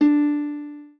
lyre_d.ogg